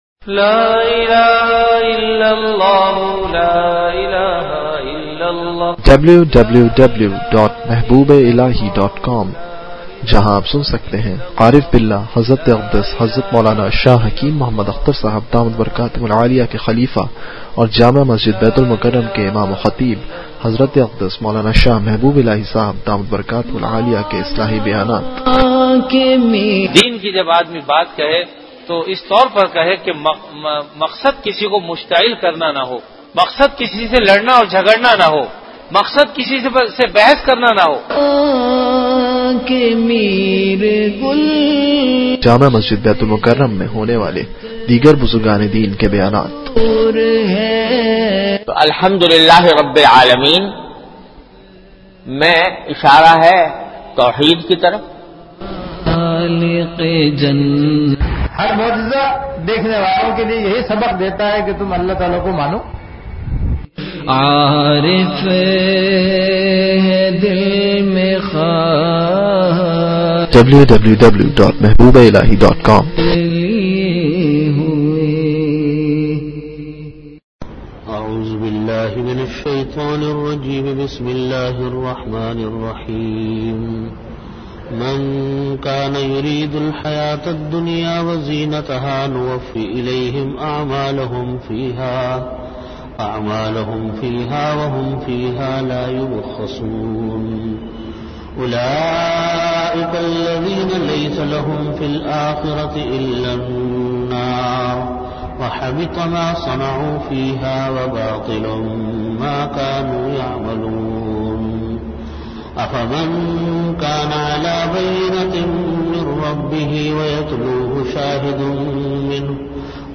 Bayanat
After Asar Prayer Venue: Jamia Masjid Bait-ul-Mukkaram, Karachi